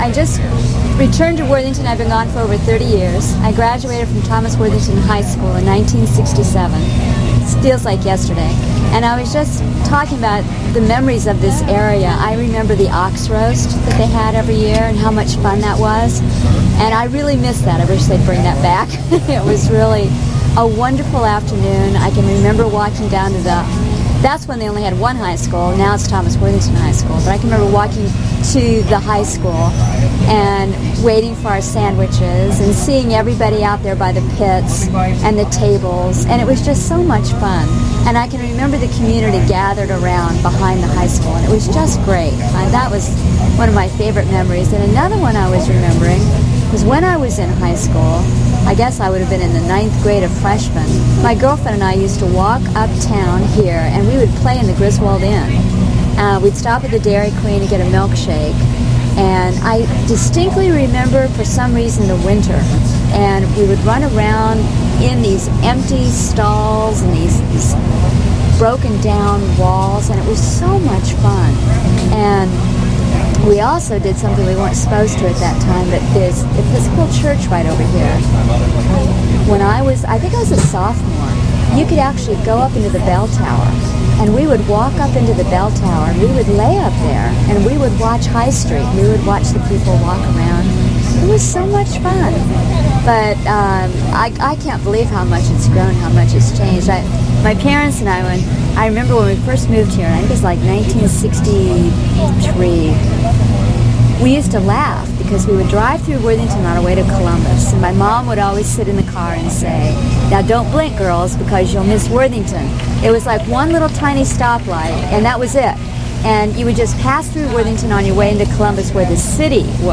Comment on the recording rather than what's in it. In July 2001 Worthington Libraries invited the community to share their earliest memories and fondest recollections of life in Worthington at the Worthington FolkFEST.